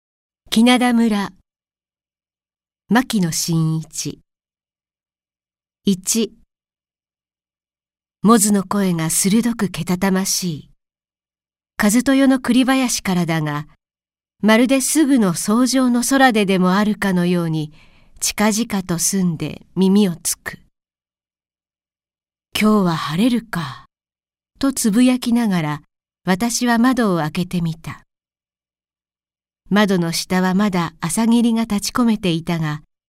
朗読ＣＤ　朗読街道140「鬼涙村・お父さんのお寝坊」牧野信一
朗読街道は作品の価値を損なうことなくノーカットで朗読しています。